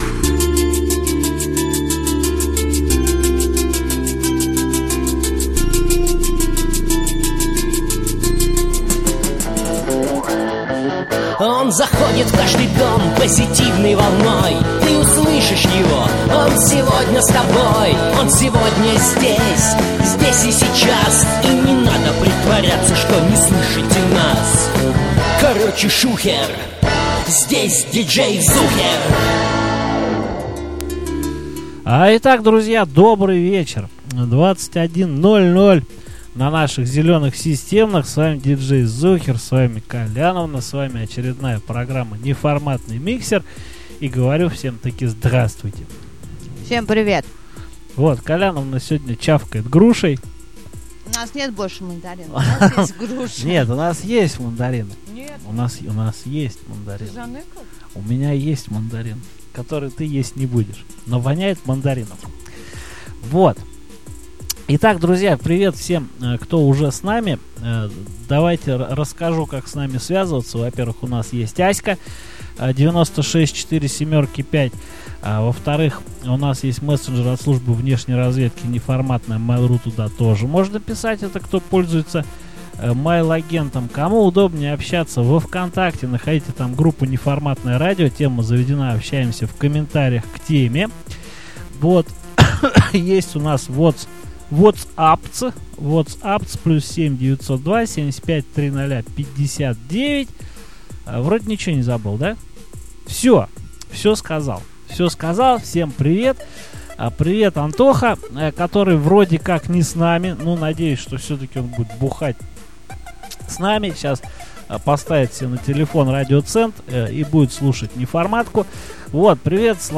Такая домашняя, немного меланхоличная, программа получилась.